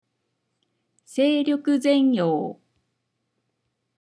pron.], translates into English as maximum efficient use of energy, to fully utilise one’s spiritual and physical energies to realise an intended purpose, something we at Westcroft Judo Club strive to teach and learn.
seiryokuzenyo.mp3